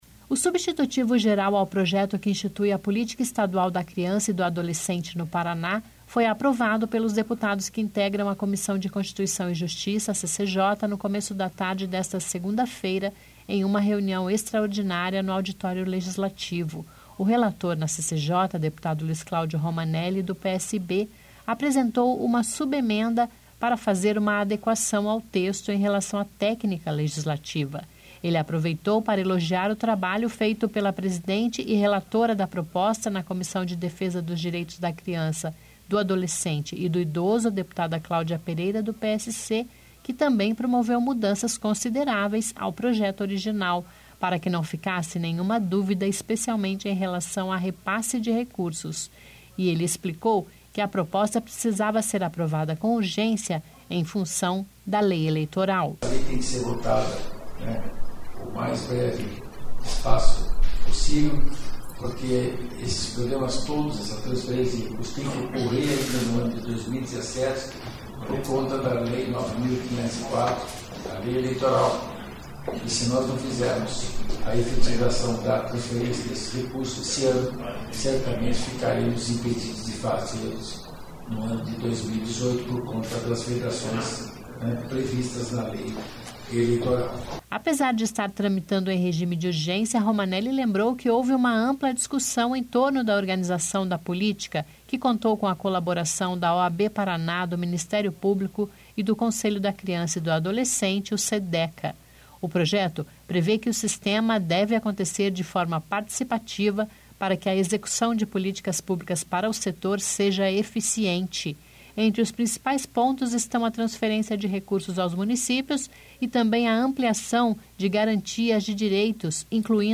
Notícia